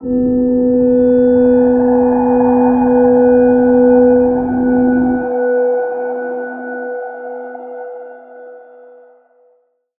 G_Crystal-B4-pp.wav